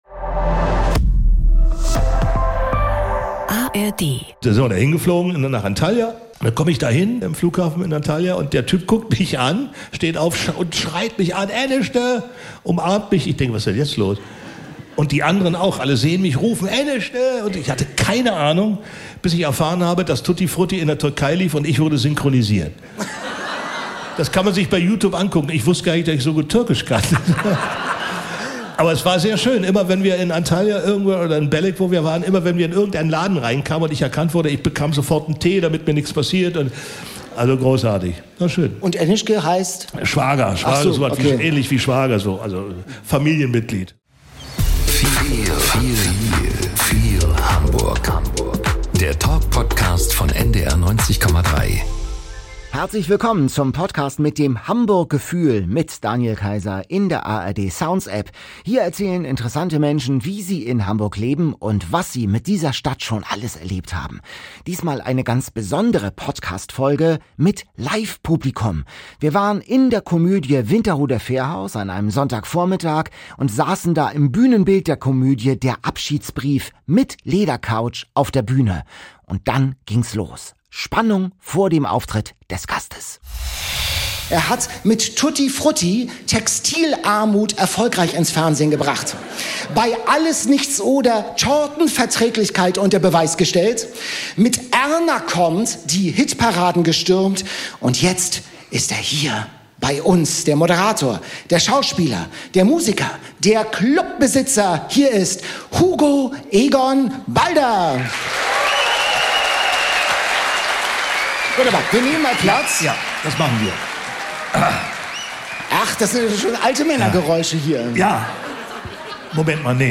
n dieser Live-Ausgabe des Podcasts Feel Hamburg aus der Komödie Winterhuder Fährhaus ist Entertainer, Moderator und Produzent Hugo Egon Balder zu Gast.
Eine Live-Podcast-Folge mit Publikum, die Unterhaltung, Zeitgeschichte und persönliche Reflexion verbindet – mit einem der prägenden Gesichter der deutschen Fernsehlandschaft.